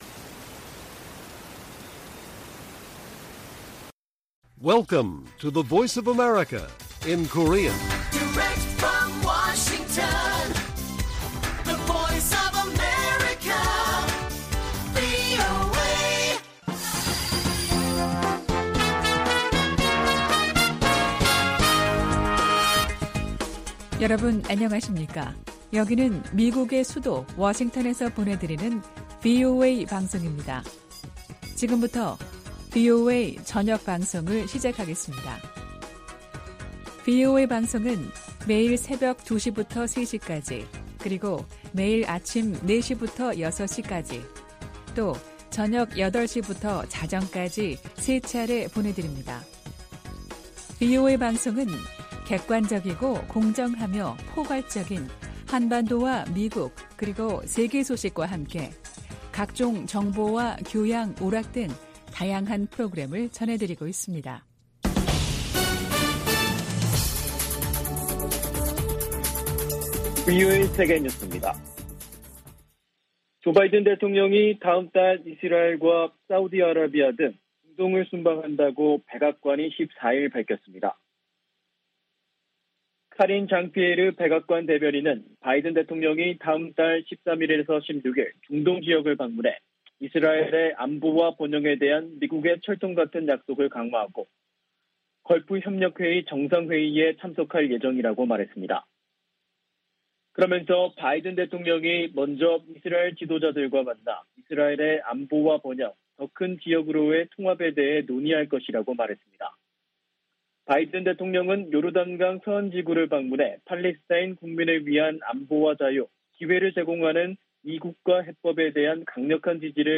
VOA 한국어 간판 뉴스 프로그램 '뉴스 투데이', 2022년 6월 15일 1부 방송입니다. 미국 정부 대북제재의 근거가 되는 '국가비상사태'가 다시 1년 연장됐습니다. 미 재무부 부장관은 북한의 거듭되는 무력시위에 응해 추가 제재 방안을 면밀히 검토하고 있다고 밝혔습니다. 미국은 한국·일본과 협의해 북한의 도발에 대한 장단기 군사대비태세를 조정할 것이라고 미 국방차관이 밝혔습니다.